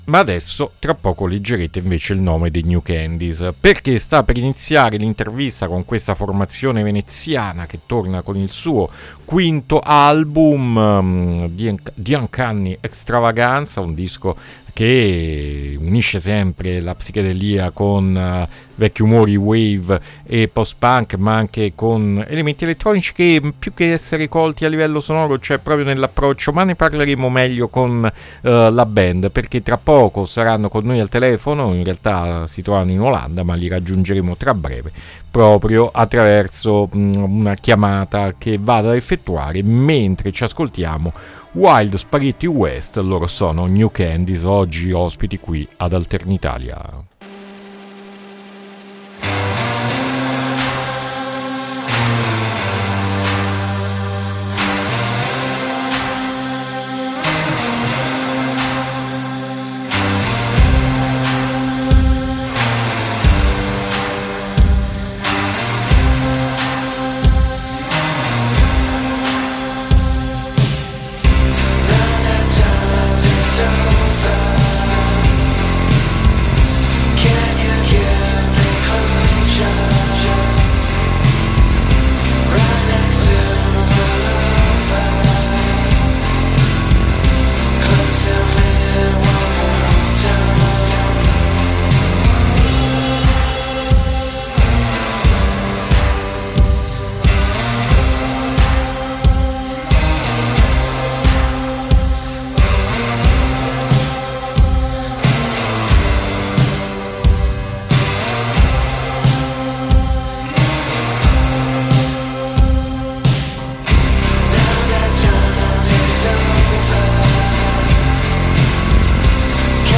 INTERVISTA NEW CANDYS AD ALTERNITALIA 6-6-2025